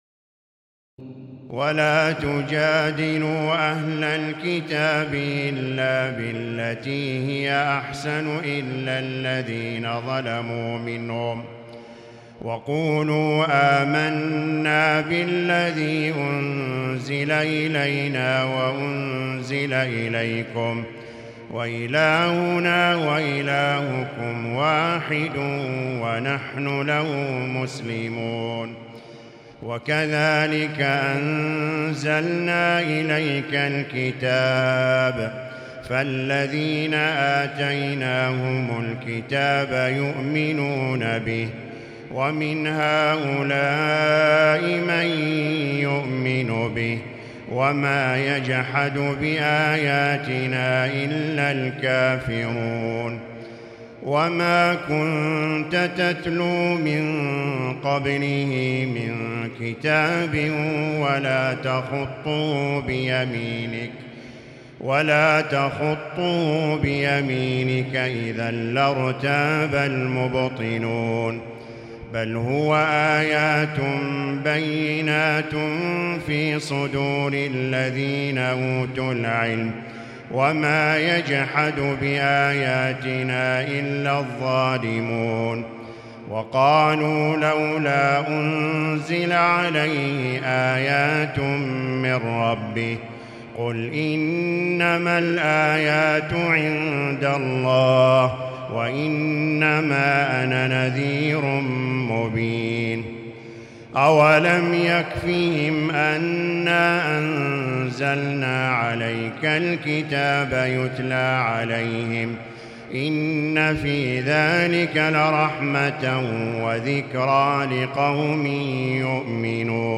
تراويح الليلة العشرون رمضان 1438هـ من سور العنكبوت (46-69) و الروم و لقمان (1-19) Taraweeh 20 st night Ramadan 1438H from Surah Al-Ankaboot and Ar-Room and Luqman > تراويح الحرم المكي عام 1438 🕋 > التراويح - تلاوات الحرمين